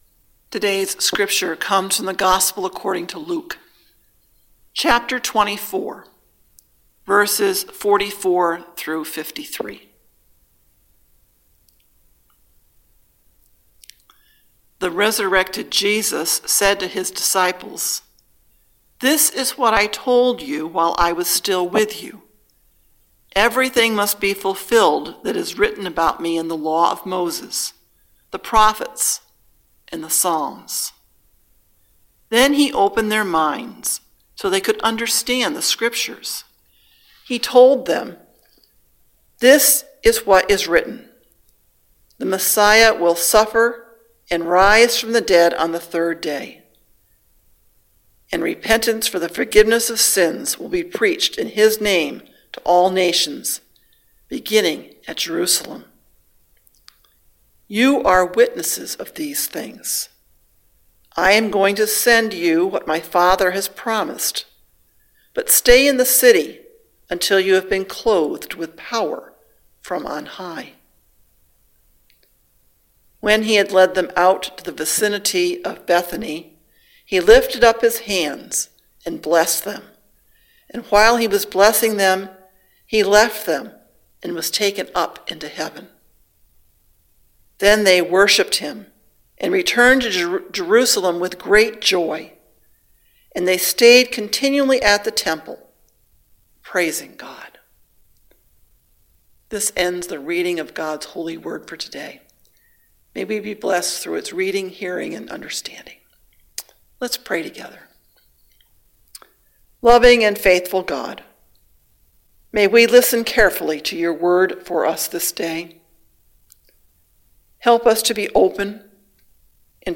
Sermon: Space X | First Baptist Church, Malden, Massachusetts
Ascension Sunday May 16